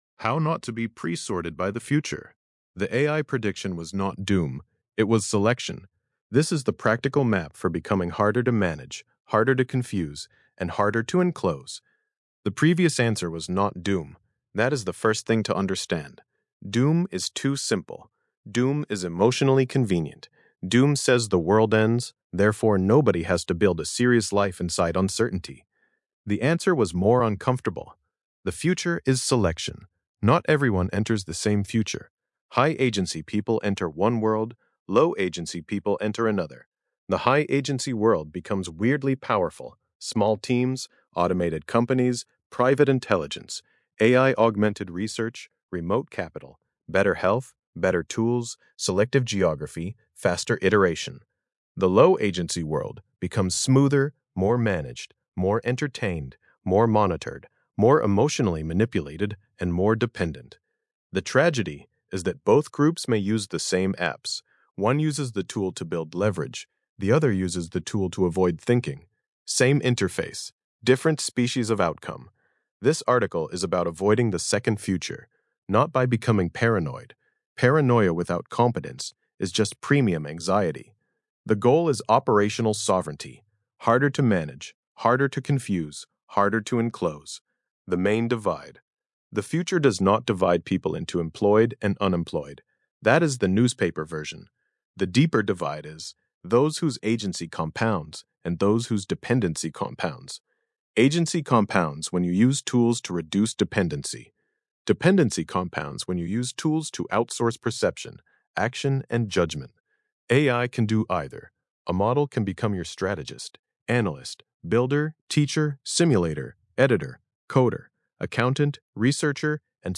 Podcast-style audio version of this essay, generated with the Grok Voice API.